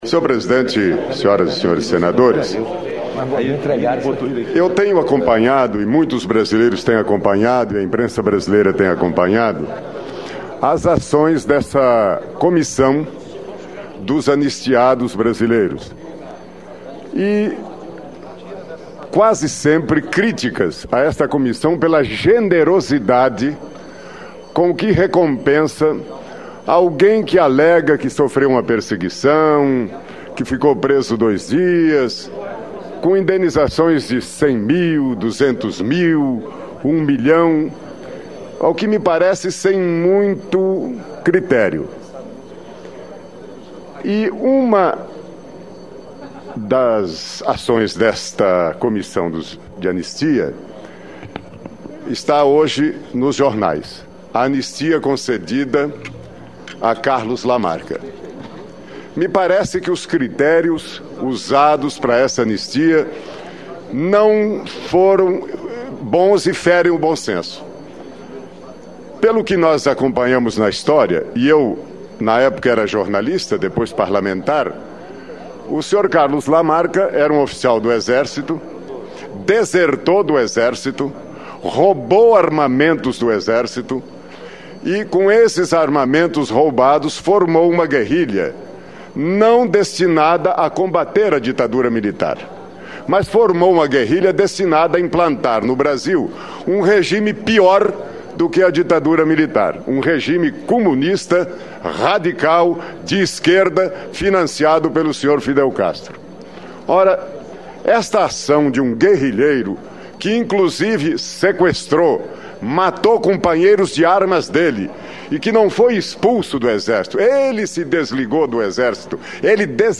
Discurso do senador Gerson Camata no Senado sobre o caso Lamarca
DiscursoSenadorGersonCamata.mp3